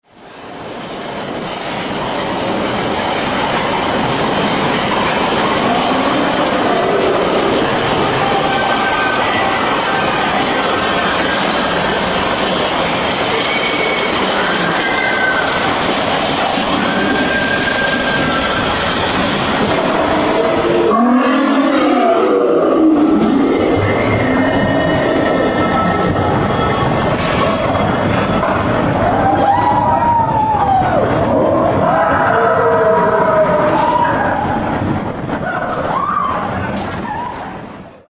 Click on Mickey to Hear a Bobsled Narrowly
Escape the Abominable Snowman.